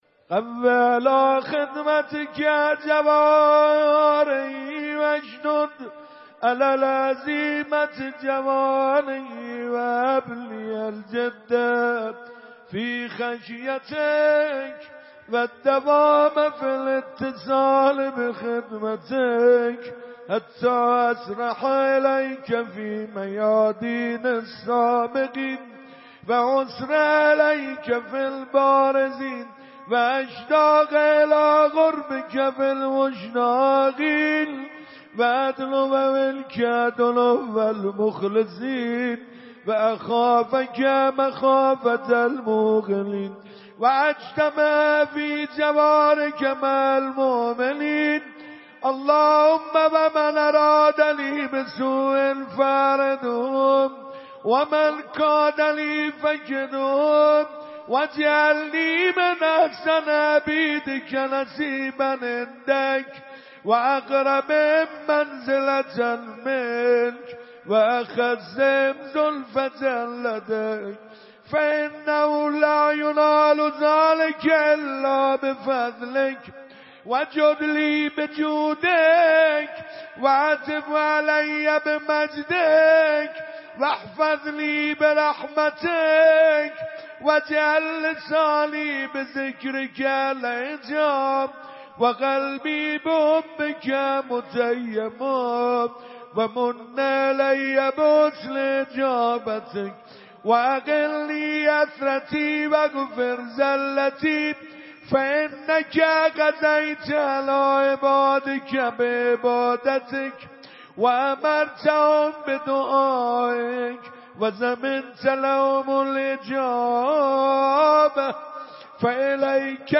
شب هفتم ماه مبارک رمضان 96 - مسجد ارک - مناجات ماه رمضان و روضه